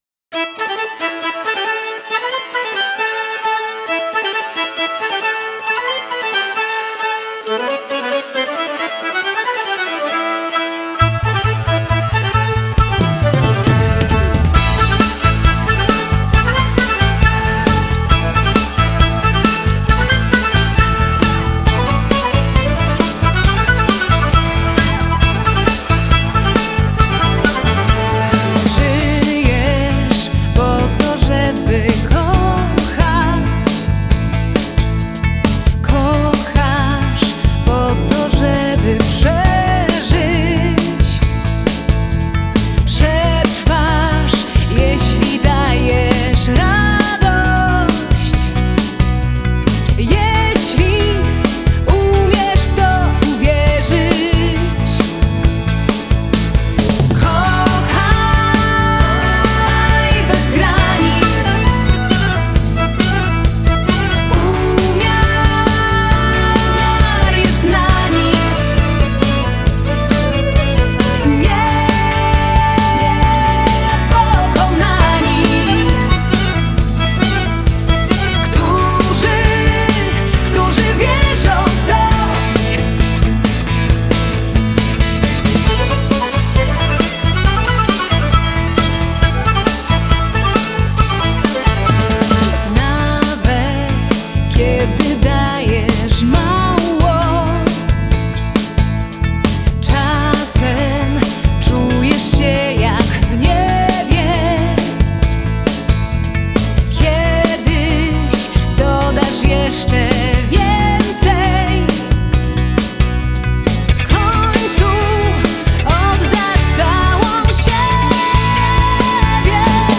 Genre: Folk-Rock